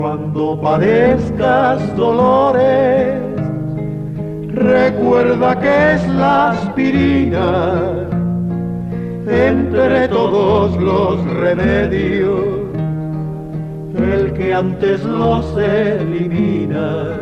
Publicitat cantada Gènere radiofònic Publicitat